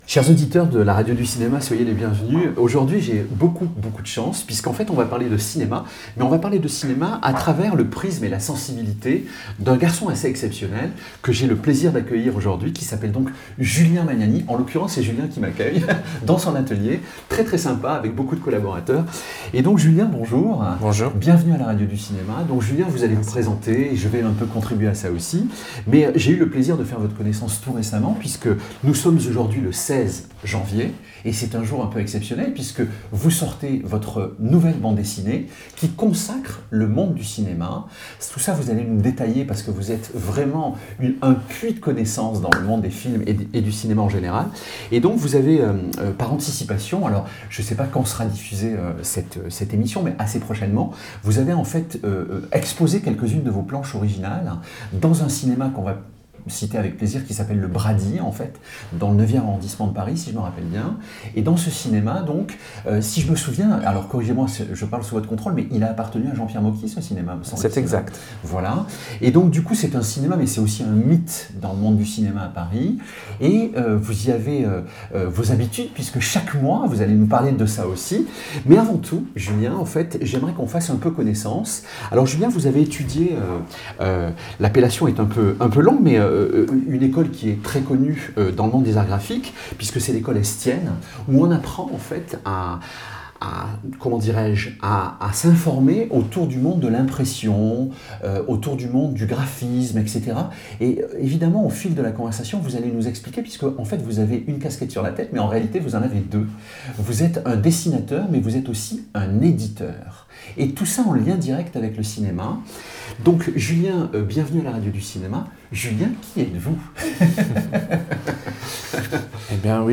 podcast cinéma avec interviews, analyses, critiques, chroniques et actualités du cinéma.